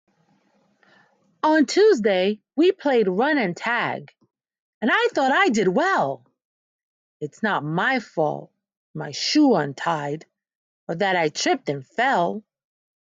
Story telling from enthusiastic teachers.